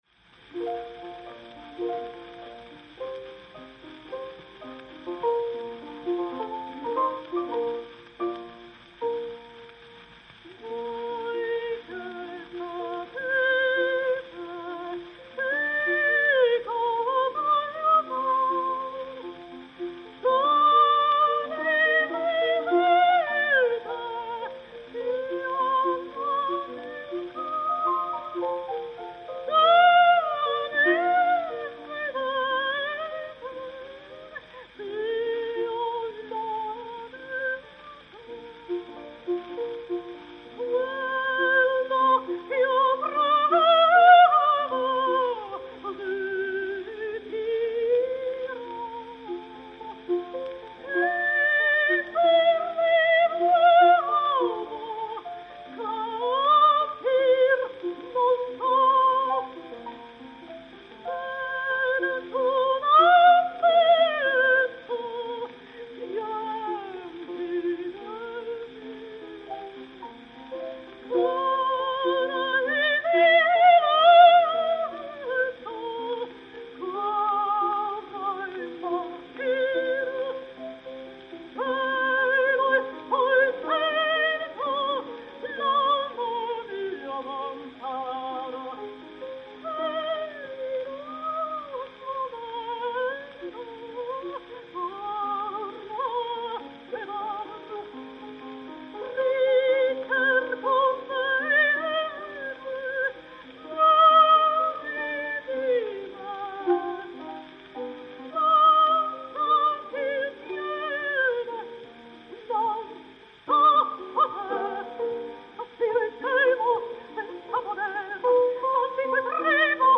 Adelina Patti (Chérubin) et Sir Landon Ronald au piano
enr. à Craig-y-Nos Castle en décembre 1905